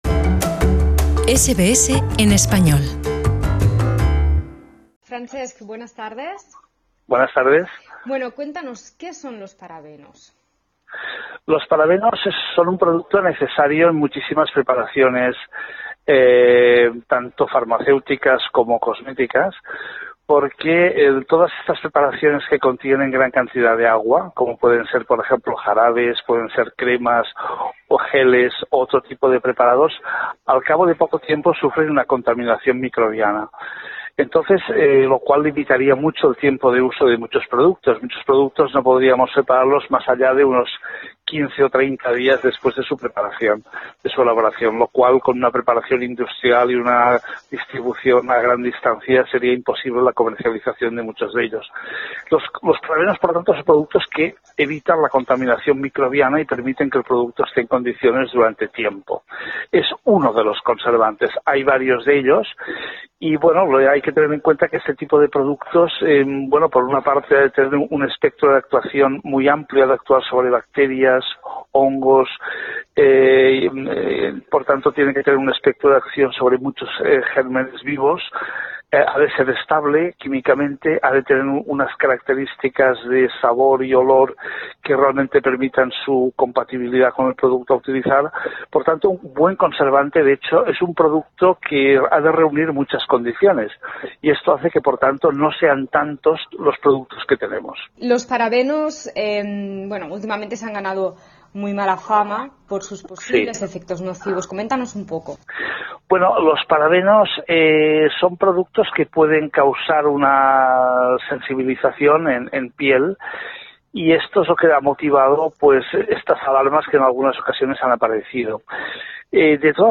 Escúchanos en Radio SBS Spanish 24/7 Puedes escucharnos por Radio Digital, a través de nuestro servicio de streaming en vivo aquí en nuestra página web o mediante nuestra app para celulares.